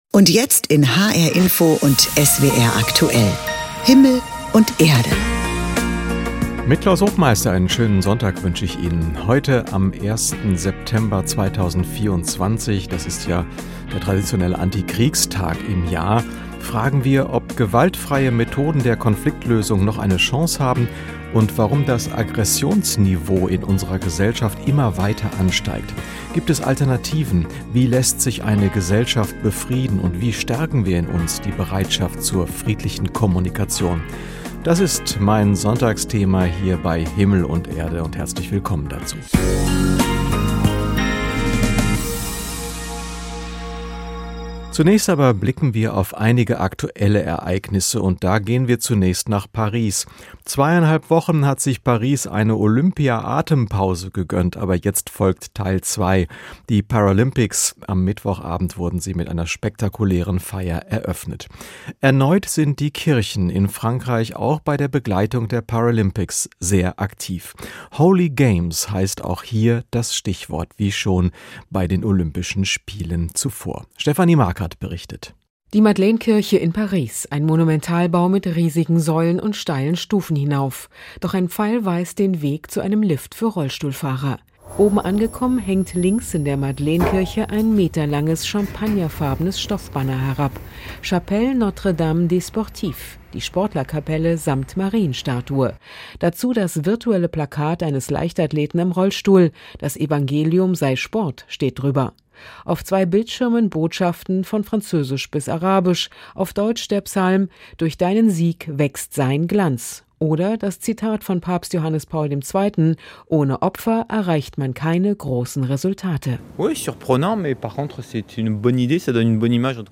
1 Stimme der Stummen - EKD-Ratsvorsitzende Kirsten Fehrs im Gespräch 25:25